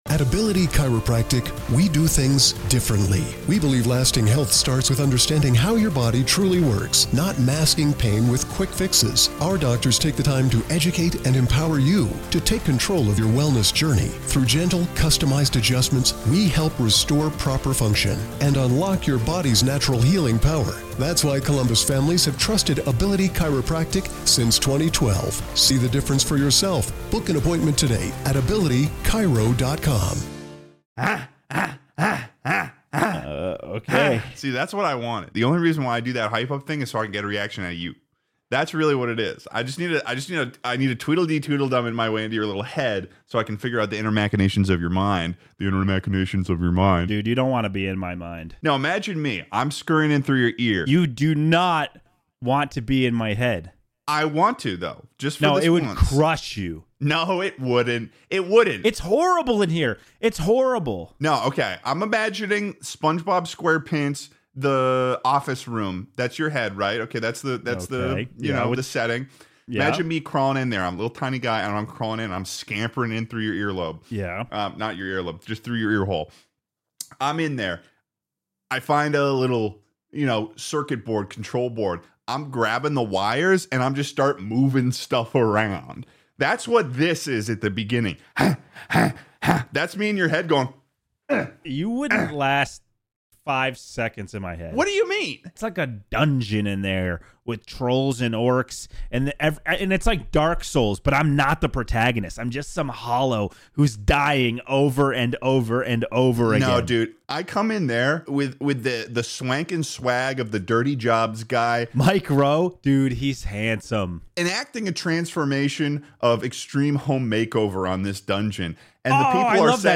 On this week's episode of Take It Easy, the men discuss the largest gaming release of the year, weird menu items that have a tight grip on society, and the worst possible thing a restaurant can serve you.